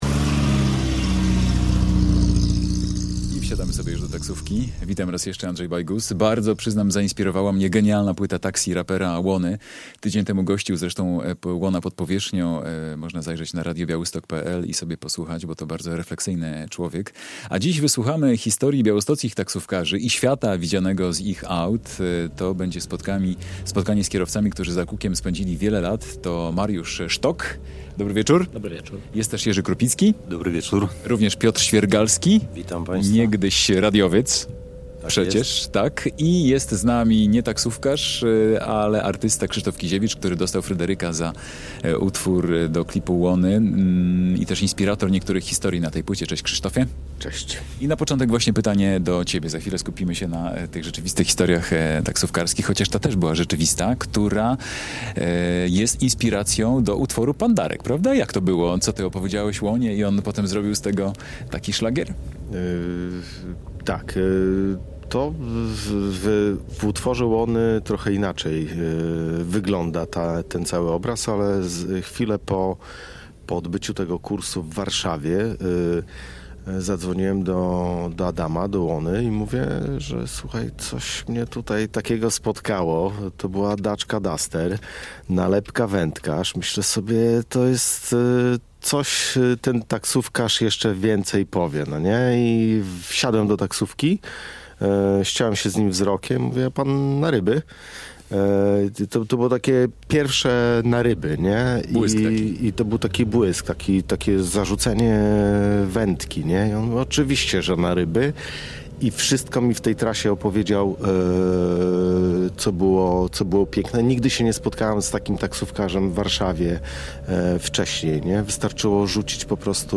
Piosenki o historiach, które wydarzyły się w taksówkach, o taksówkarzach, świecie widzianym z okna ich aut. O swoich doświadczeniach opowiadają białostoccy taksówkarze